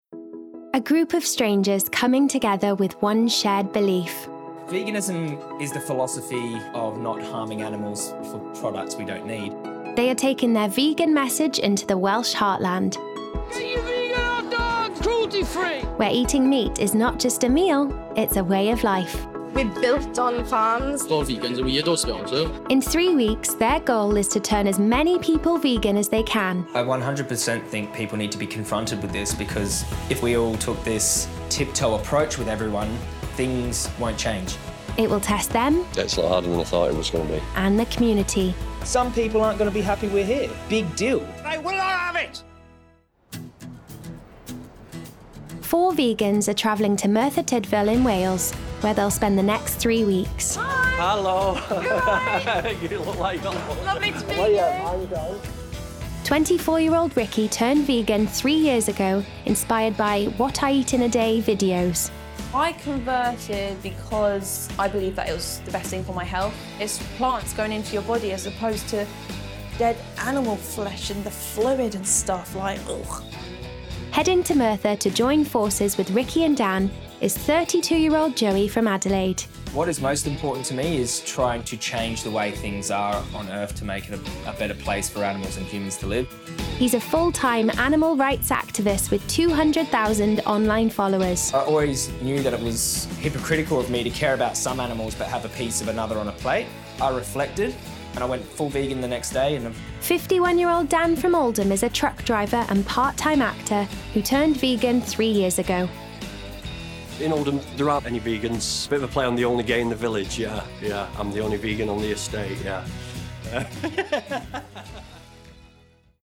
20/30's Welsh/Neutral,
Bright/Youthful/Fun
• Documentary